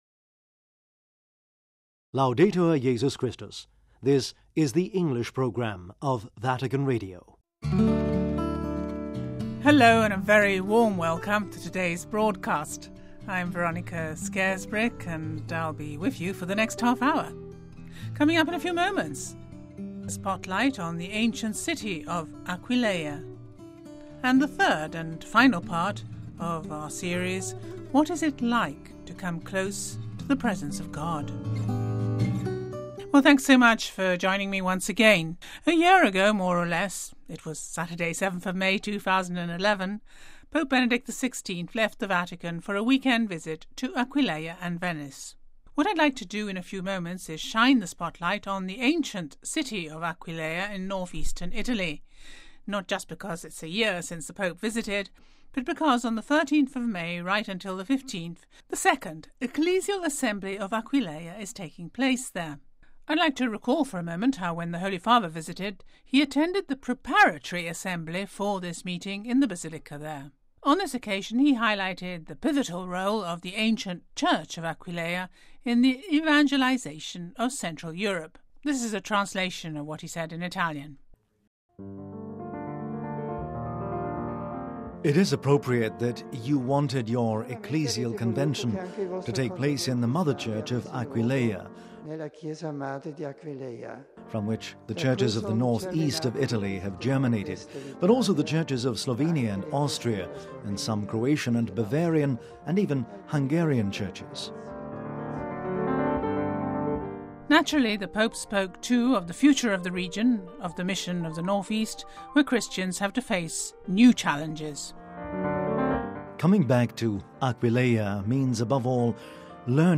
In this programme you can also hear excerpts from Pope Benedict's homily delivered in the Basilica there a year ago .